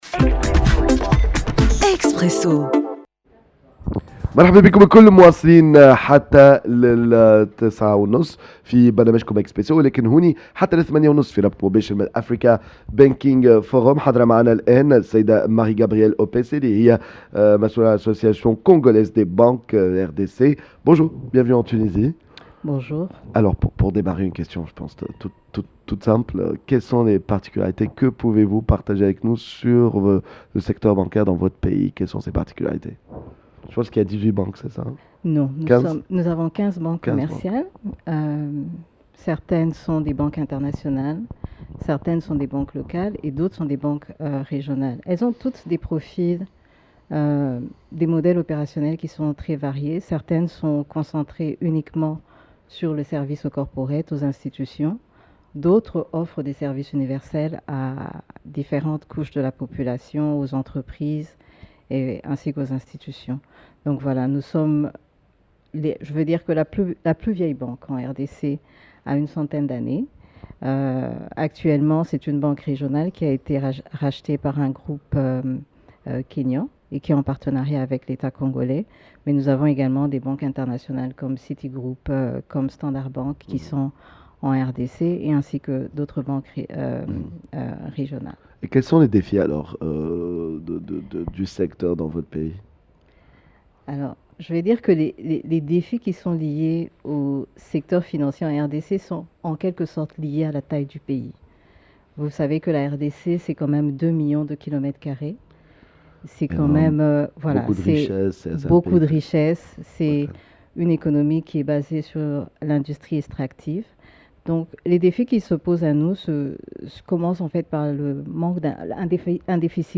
à l’occasion de la 16ème édition de l’Africa Banking Forum